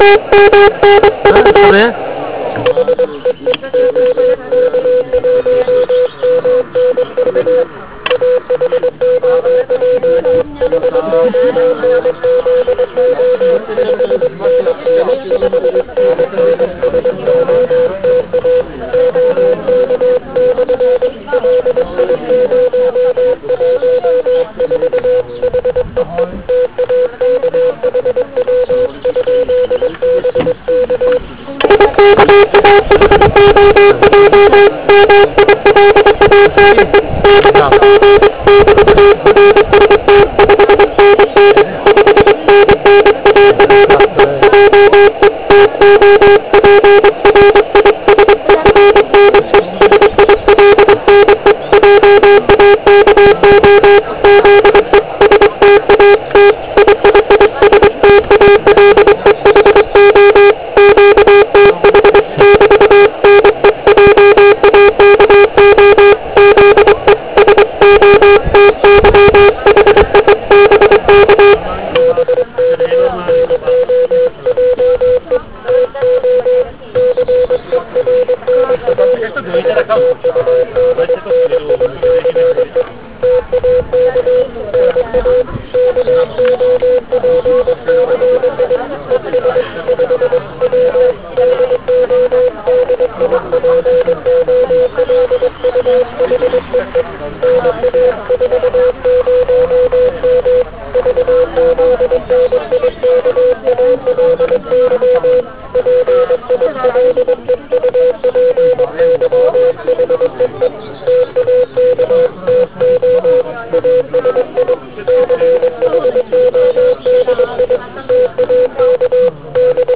Jak sami z nahrávek můžete zjistit, byl operátor "zahlušován" dalšími zvuky HI.